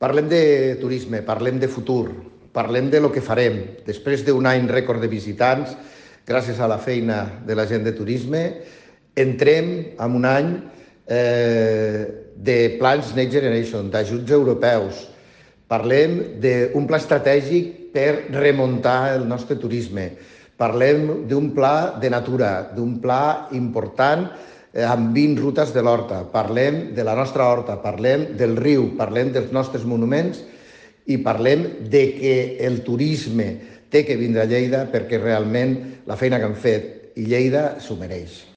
tall-de-veu-del-tinent-dalcalde-paco-cerda-sobre-la-reunio-del-consell-rector-de-turisme-de-lleida